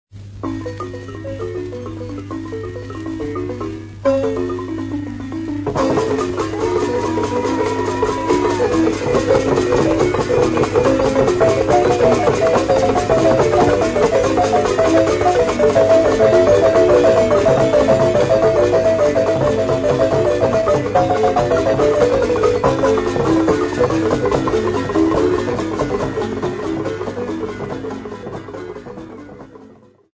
TP4178-DXYZTL4630B.mp3 of Ngodo xylophone movement